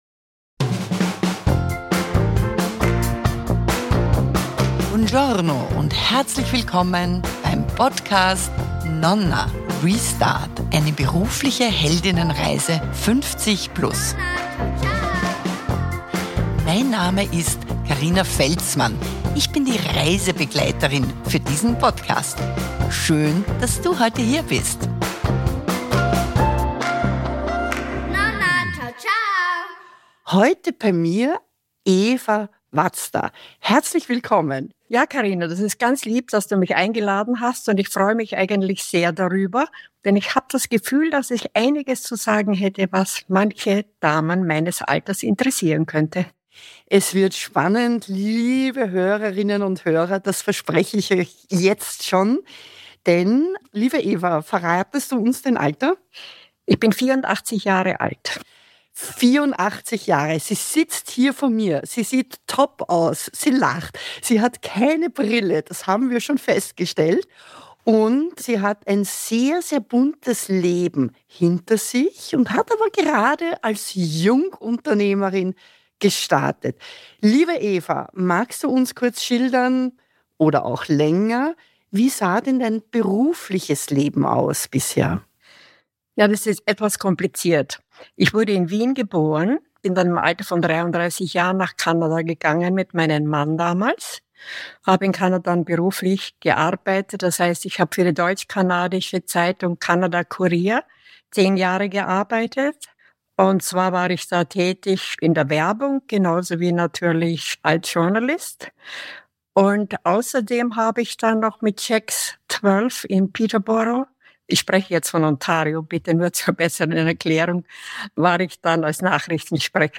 Spätestens in diesem Interview wird klar – das Alter ist zwar eine Art Information, sagt aber nichts über die Lebensenergie, den Esprit und den Willen von Menschen aus.